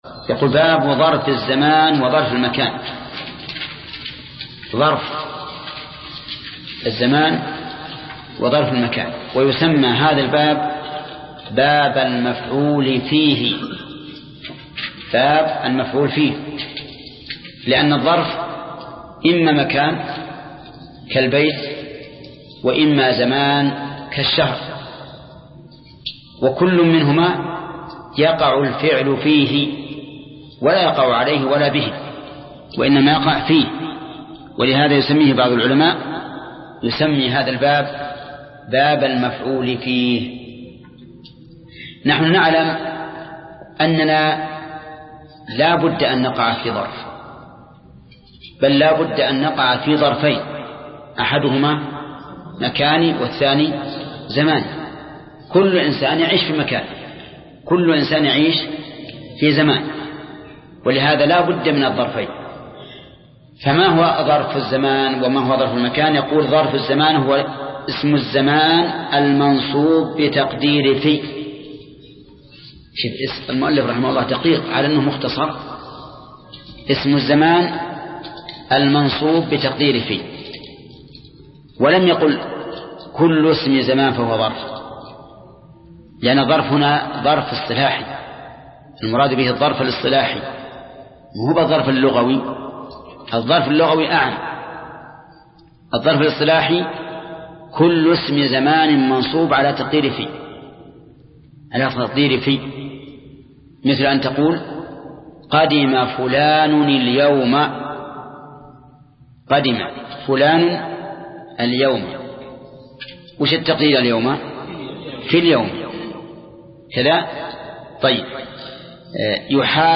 درس (19) : شرح الآجرومية : من صفحة: (389)، قوله: (باب ظرف المكان وظرف الزمان).، إلى صفحة: (413)، قوله: (باب التمميز).